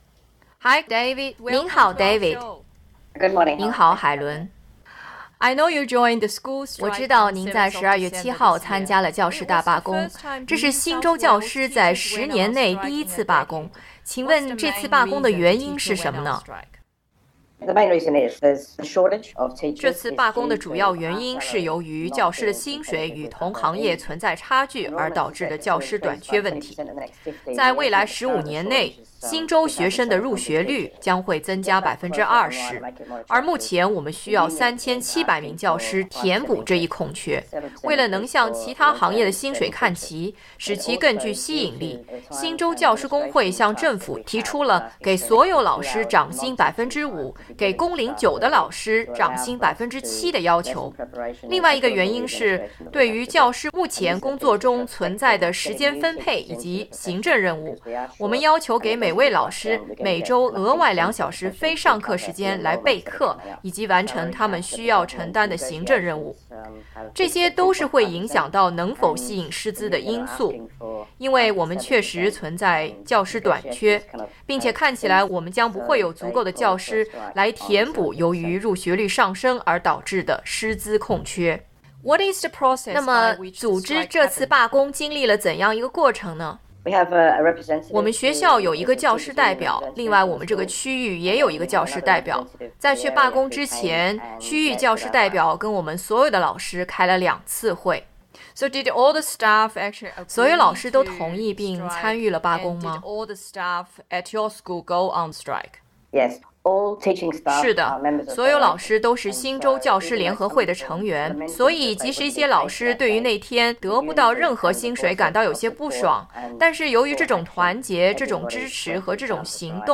（特别提醒：由于受访者不愿透露个人真实姓名，这个采访对本人的声音做了一些处理。